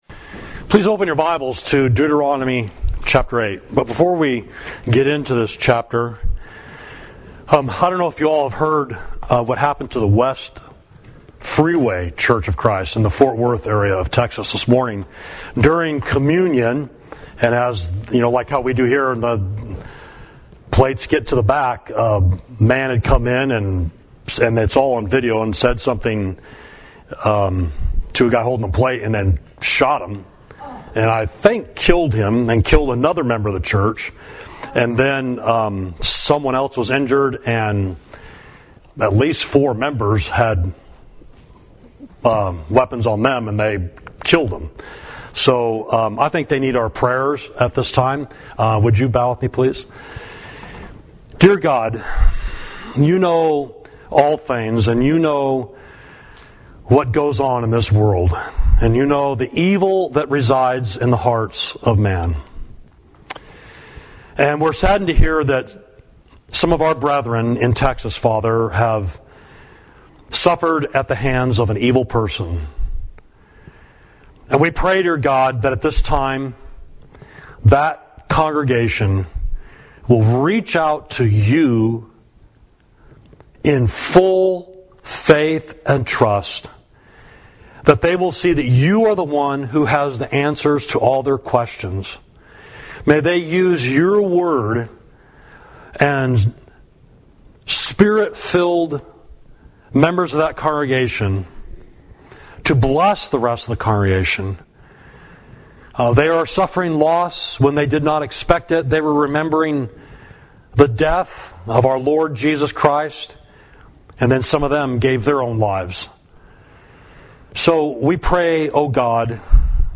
Sermon: The Ultimate New Year’s Resolution, Deuteronomy 8